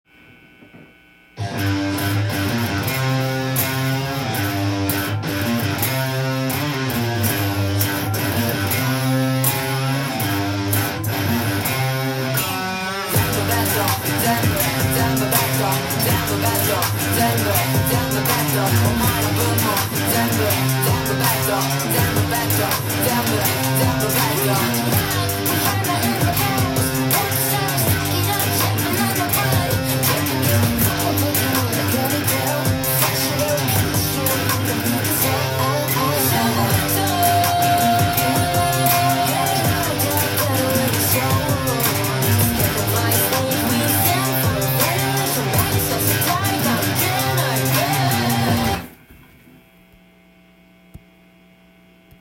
音源に合わせて譜面通り弾いてみました
この曲は、ヘビーなギターリフが何度も繰り返される曲です。
keyがGmになるので
エレキギターで歪ませてカッコよく
ロックな感じで弾くことが出来ます。